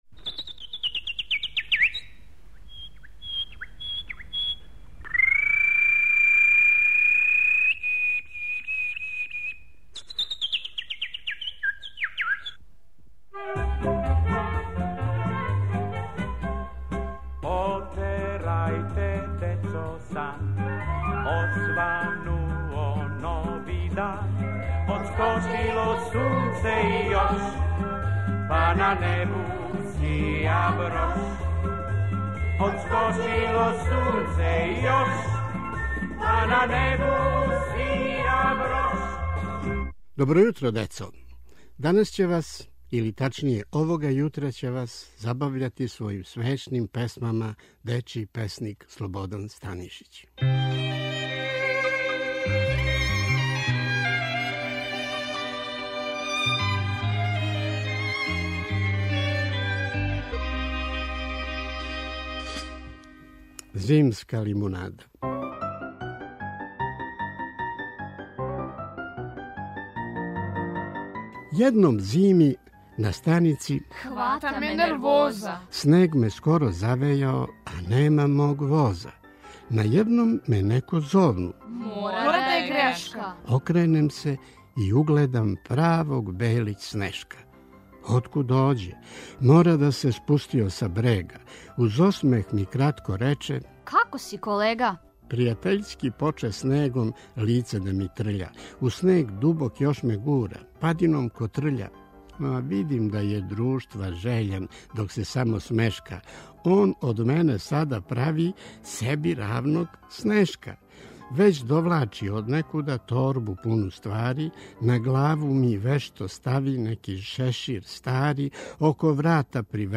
Поезија
у пратњи глумаца из Дечије драмске групе Радио Београда